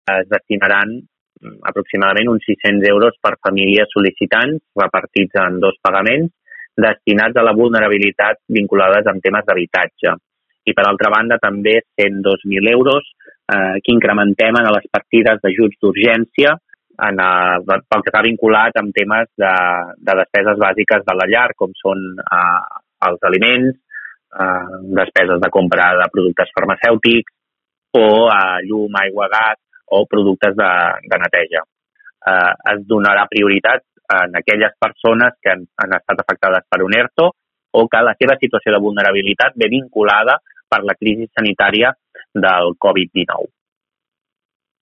En parla Jofre Serret, primer tinent d’alcalde de Malgrat.